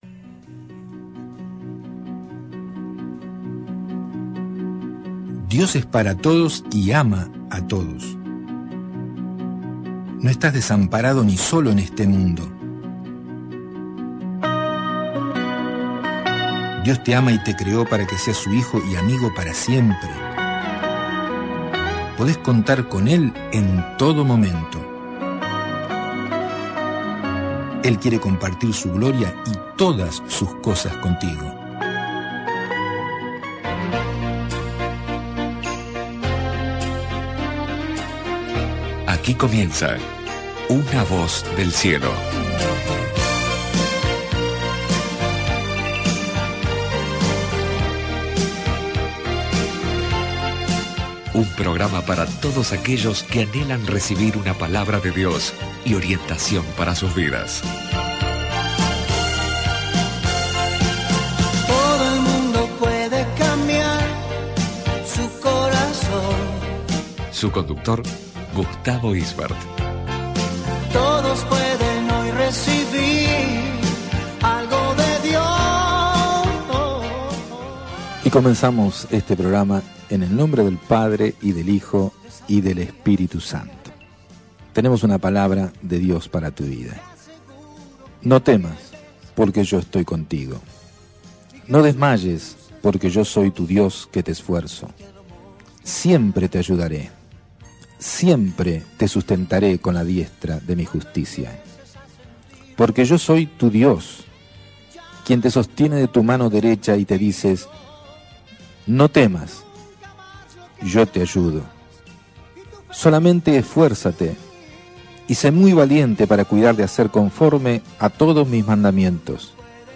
Una Voz del Cielo: Programa de radio Cristiano para regalar